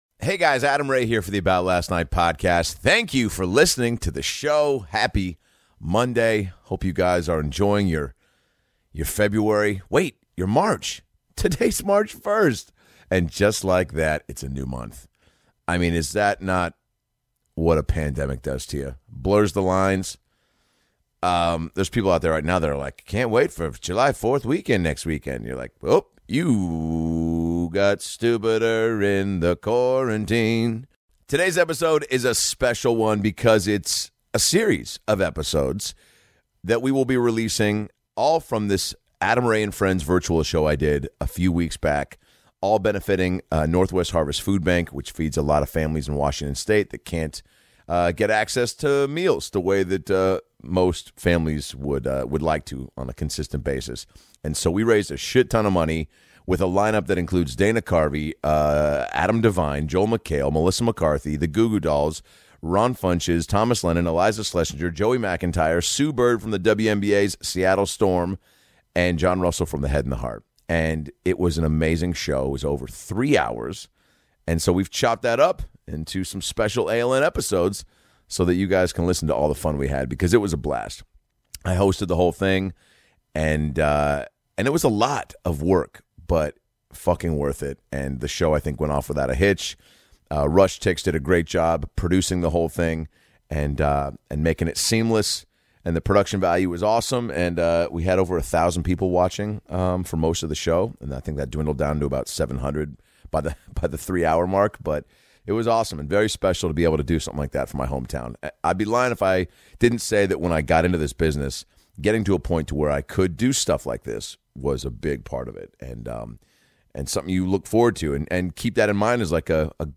ADAM RAY & FRIENDS PART 1 Joey McIntyre, Sue Bird, Dana Carvey, John Russell from THE HEAD & THE HEART On Feb 5th 2021, Adam hosted ADAM RAY & FRIENDS, a star studded comedy show benefiting NW HARVEST FOOD BANK. The lineup included Melissa McCarthy, Dana Carvey, Sue Bird, Adam Devine, Iliza Shlesinger, Thomas Lennon, Joey McIntyre, Joel McHale, John Russell from The Head & The Heart, Ron Funches and Johnny Rzeznik The Goo Goo Dolls!